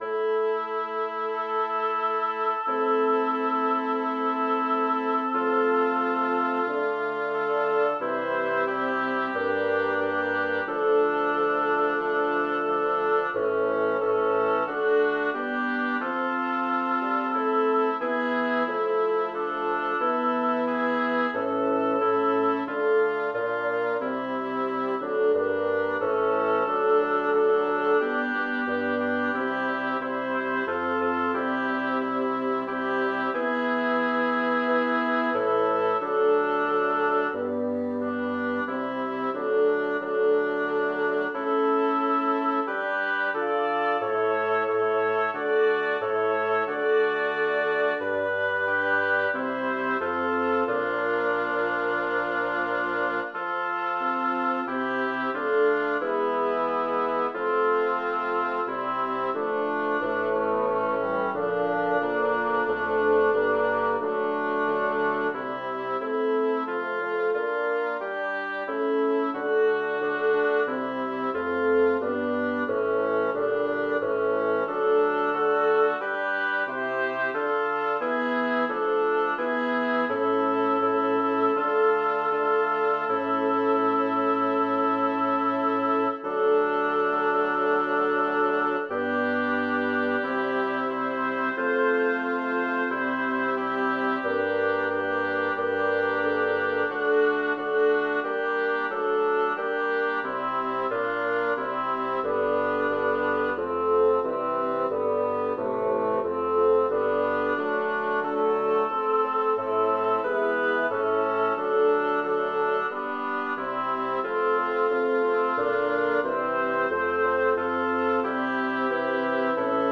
Title: Will dann nicht anders werden draus Composer: Jacob Meiland Lyricist: Number of voices: 5vv Voicing: SATTB Genre: Sacred, Sacred song
Language: German Instruments: A cappella